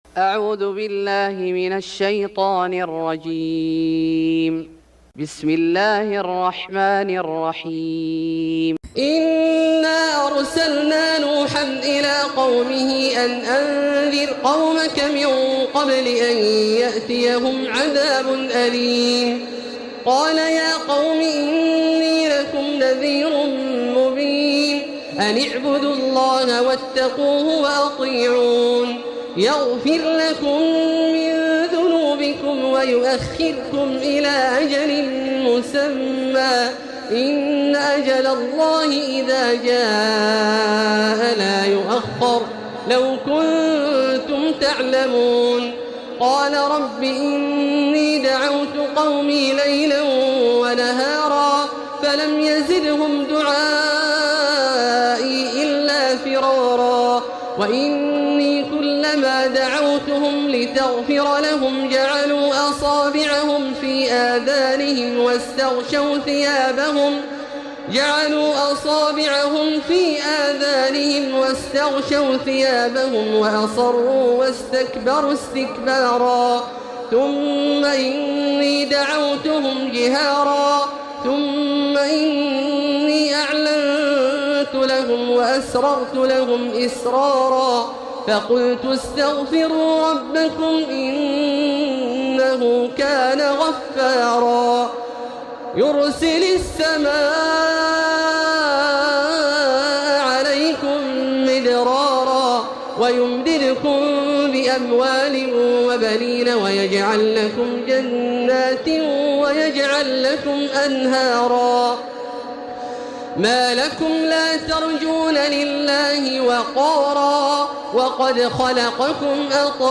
سورة نوح Surat Nuh > مصحف الشيخ عبدالله الجهني من الحرم المكي > المصحف - تلاوات الحرمين